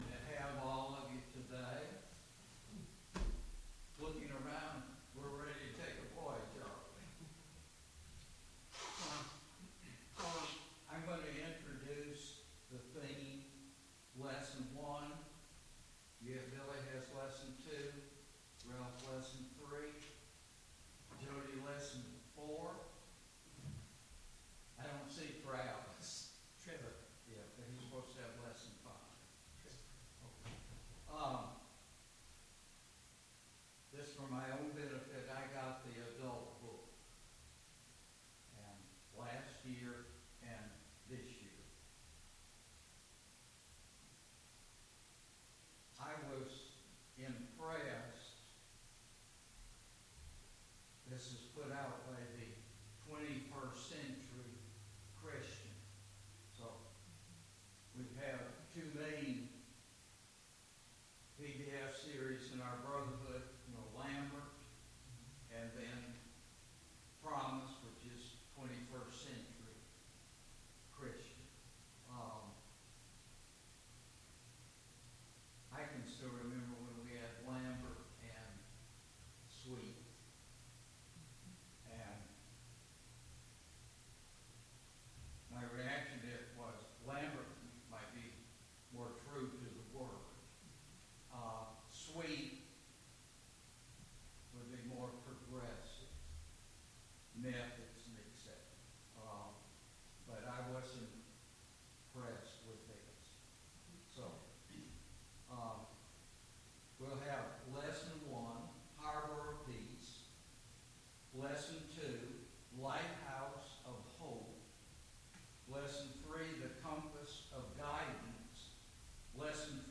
Service Type: VBS Adult Class